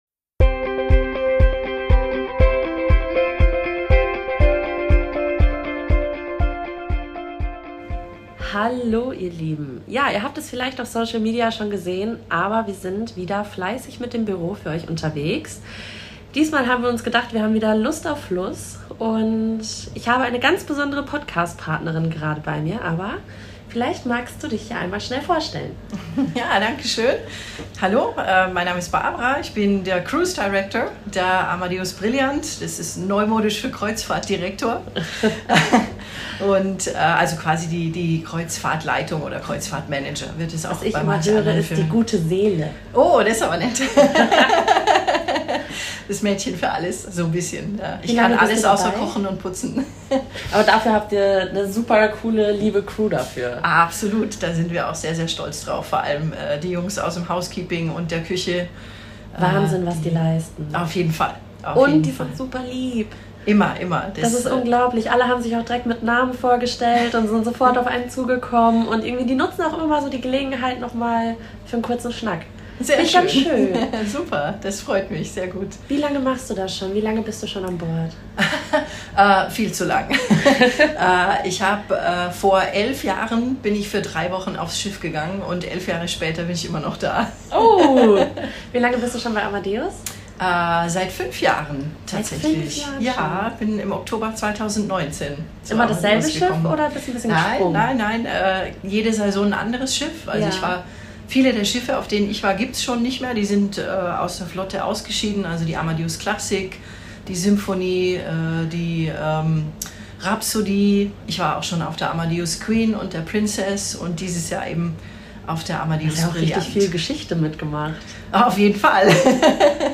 Lust auf Fluss? Wir nehmen euch mit auf die Amadeus Brilliant.